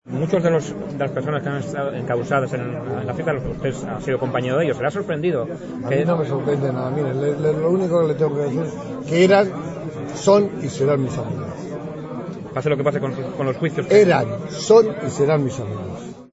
El presidente de la Real Federación Española de Fútbol, en declaraciones recogidas por la Televisión del Principado de Asturias, ha participado este jueves en la inauguración de unas obras de remodelación del antiguo campo del Tudelense, que fue adquirido por la Federación Asturiana de Fútbol para desarrollar actividades fundamentalmente de los equipos base de la zona.